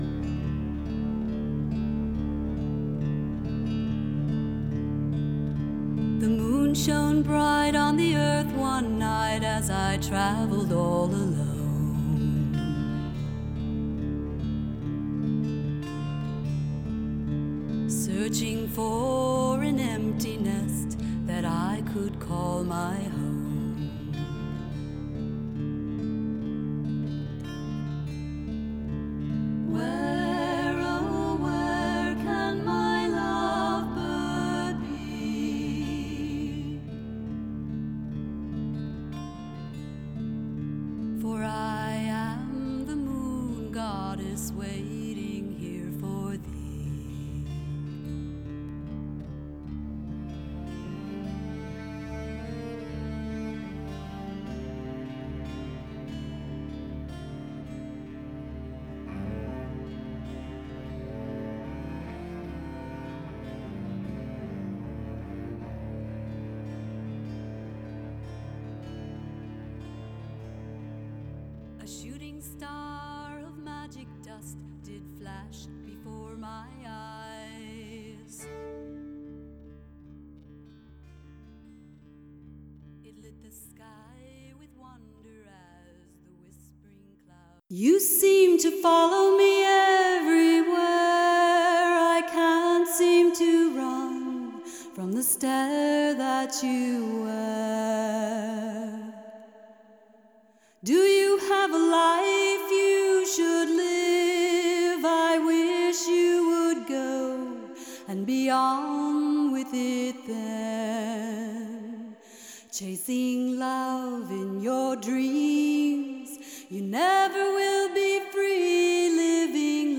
Folk singer/songwriter